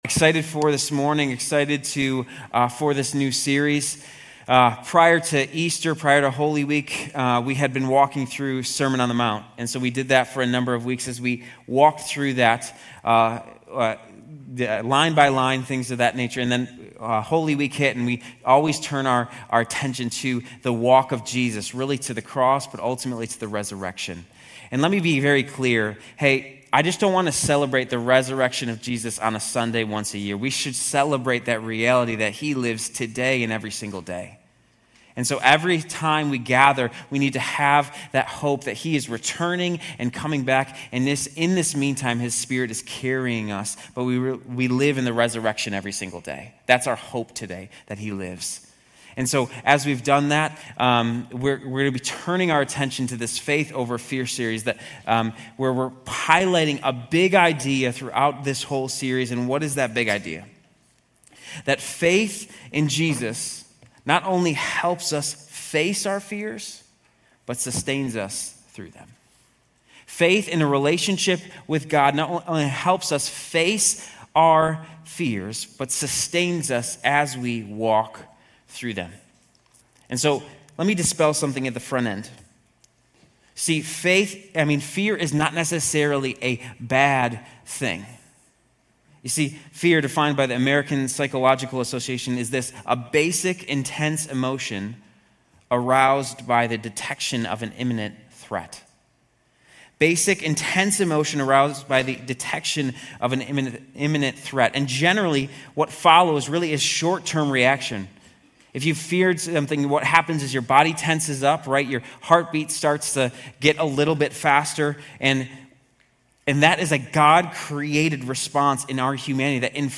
Grace Community Church University Blvd Campus Sermons 4_27 University Blvd Campus Apr 27 2025 | 00:28:57 1x 00:00 / 00:28:57 Subscribe Share RSS Feed Share Link Embed